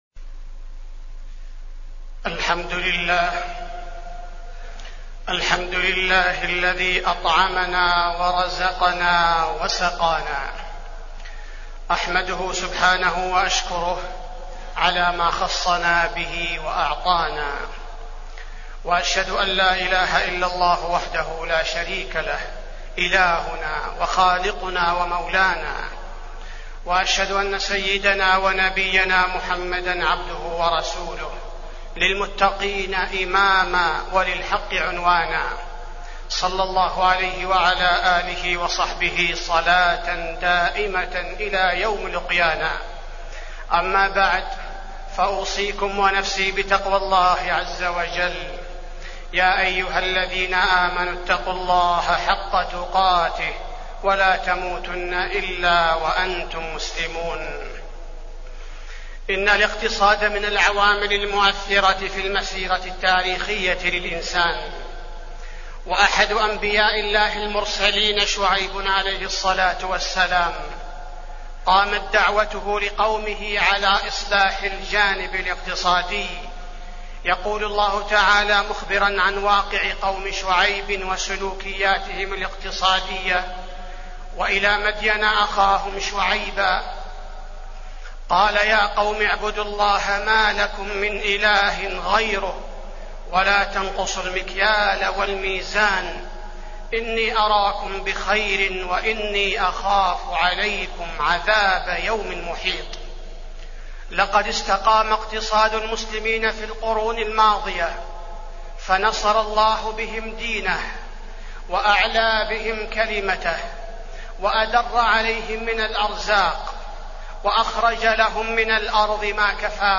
تاريخ النشر ٩ ذو القعدة ١٤٢٩ هـ المكان: المسجد النبوي الشيخ: فضيلة الشيخ عبدالباري الثبيتي فضيلة الشيخ عبدالباري الثبيتي الإقتصاد الإسلامي